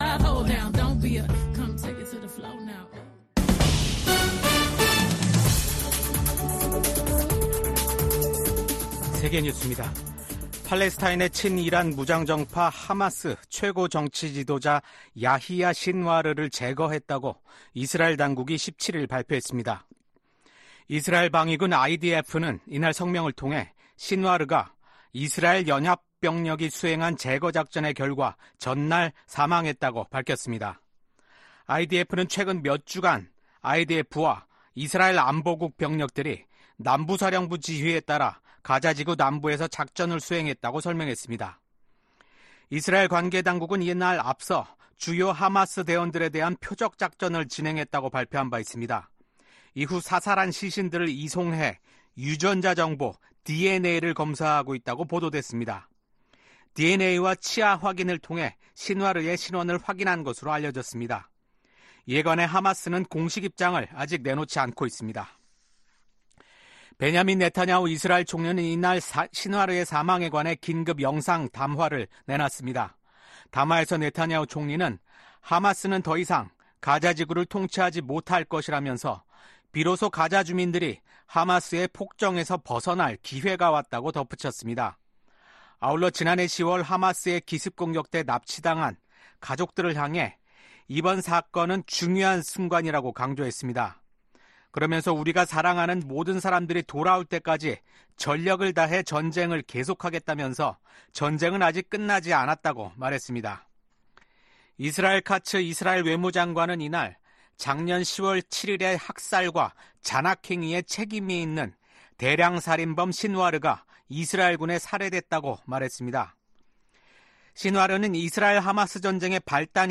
VOA 한국어 아침 뉴스 프로그램 '워싱턴 뉴스 광장' 2024년 10월 18일 방송입니다. 북한이 한국을 적대국가로 규정한 내용을 담아 헌법을 개정한 것으로 파악됐습니다. 한국 정부는 반통일 반민족적 행위라고 규탄했습니다. 캄보디아 정부가 북한을 방문했던 선박과 이 선박이 싣고 있던 석탄을 압류했습니다.